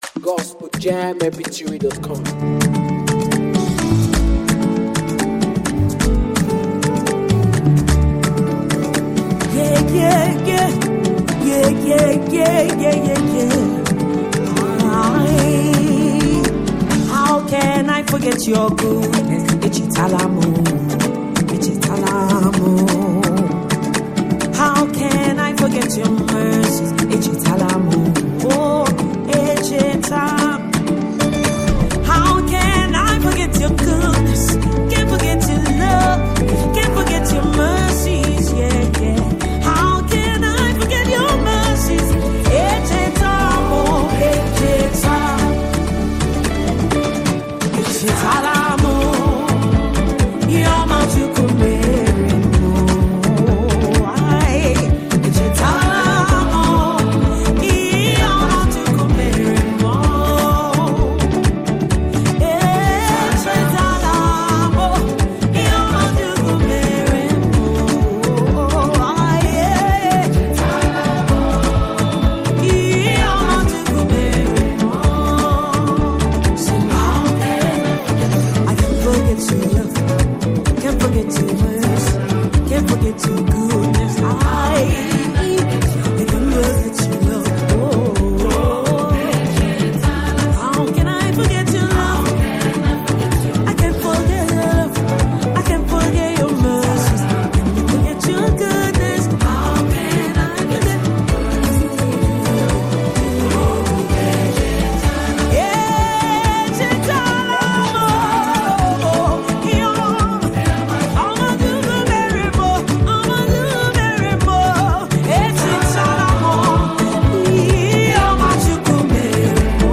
African praisemusic
powerful Dance hall song